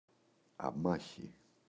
αμάχη, η [aꞋmaçi]